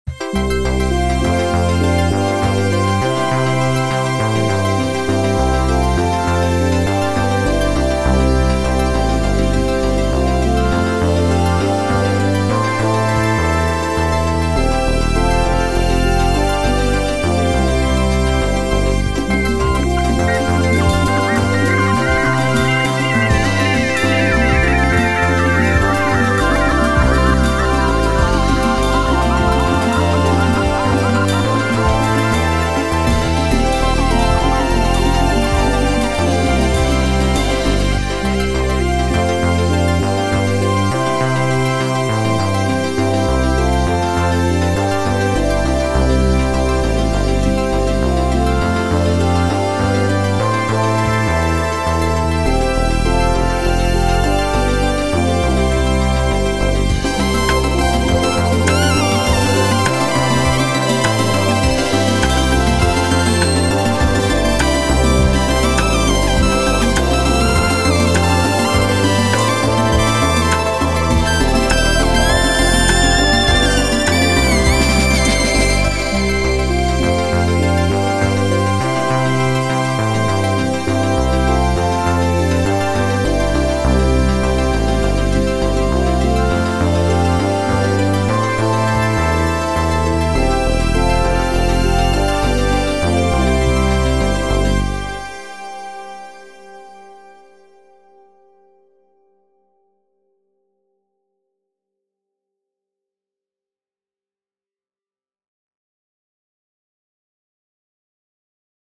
Today I decided to start a series where I use various presets from one instrument, beginning with the Analog instrument. I also used the MIDI arpeggiator chord preset and then added some drums. I’m starting to learn to play drums, too, so please excuse my mess!